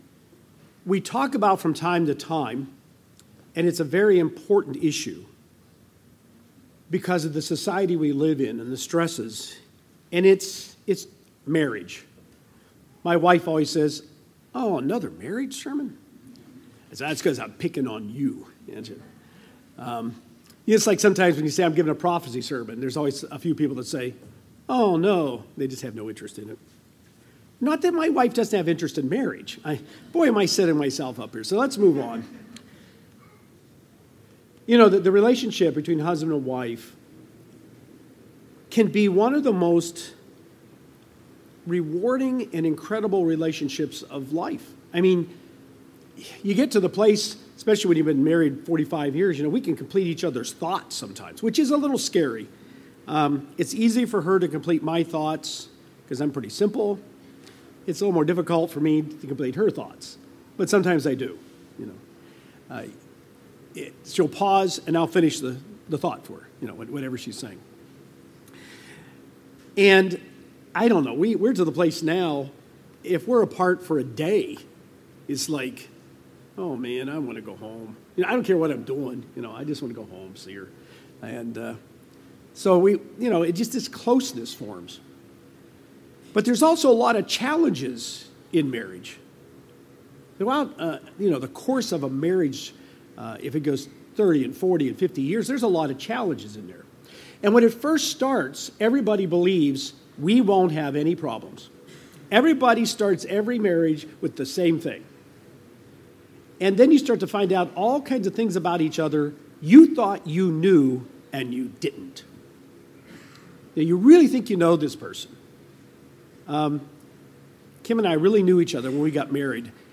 Sermon on the relationship with your spouse. Things that affect your relationship and how to have a successful marriage.